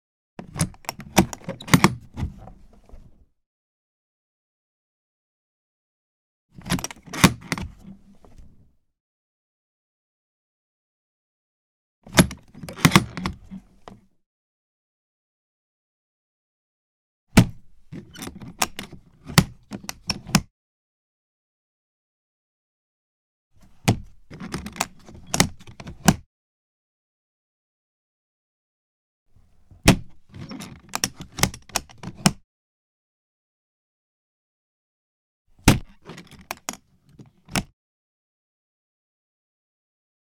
household
Flight Case Unlock and Open Lid